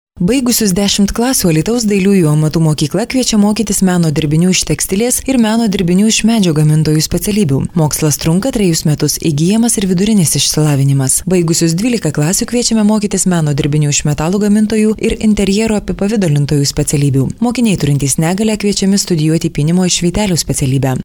Lithuanian female voiceover